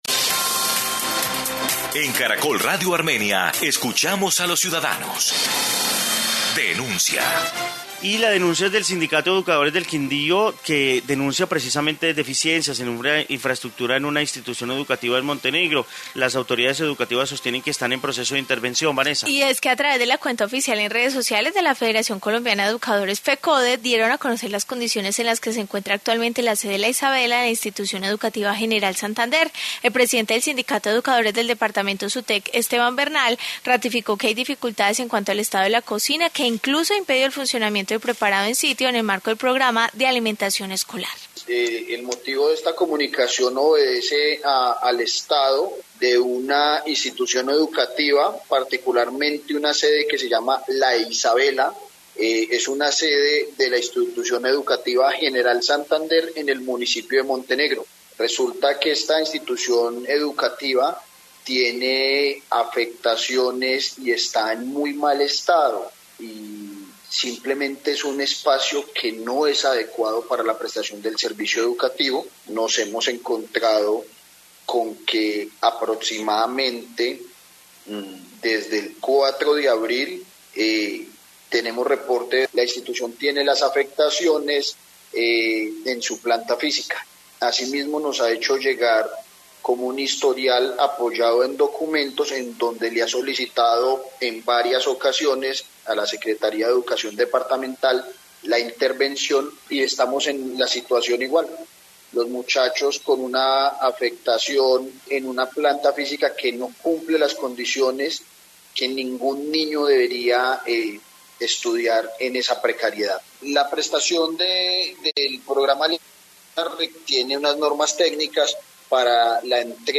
Informe colegio del Quindío